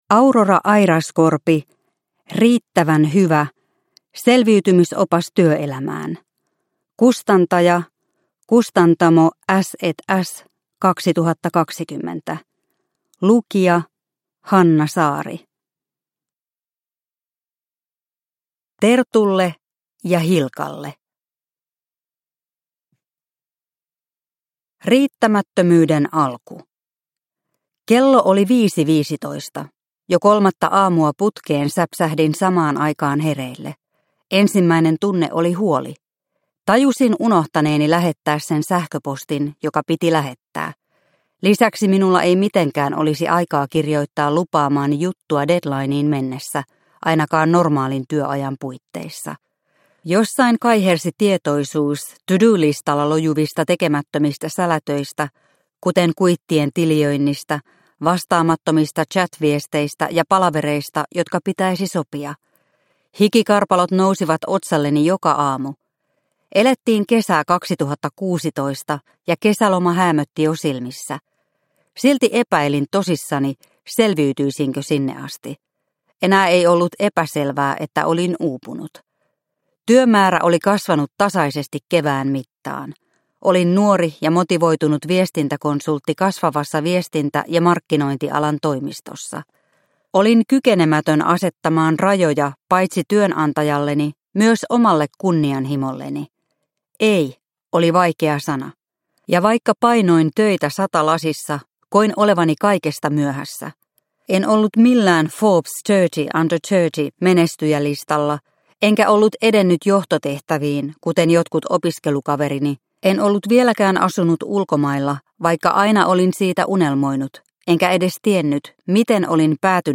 Riittävän hyvä – Ljudbok – Laddas ner